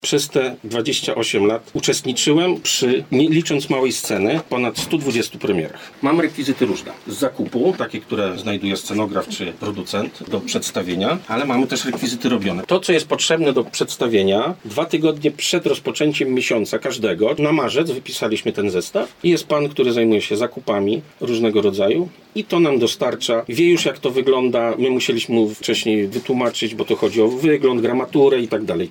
zwiedzanie Teatru Osterwy